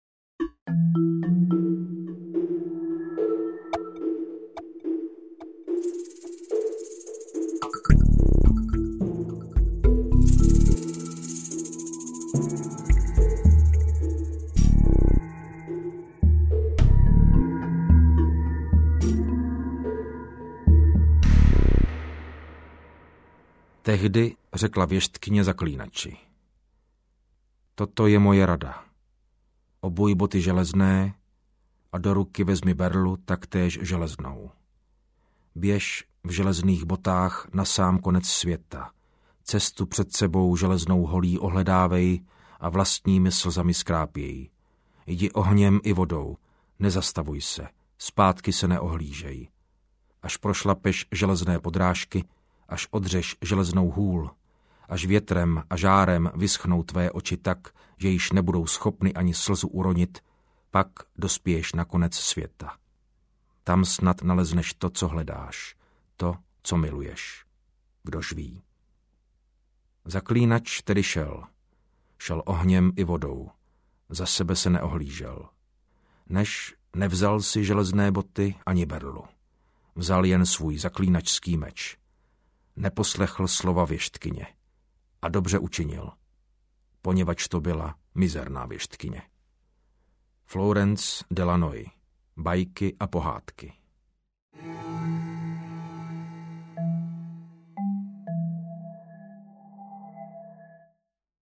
Krev Elfů audiokniha
Ukázka z knihy
• InterpretMartin Finger